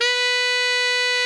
Index of /90_sSampleCDs/Giga Samples Collection/Sax/GR8 SAXES FF
TNR FFF-B4.wav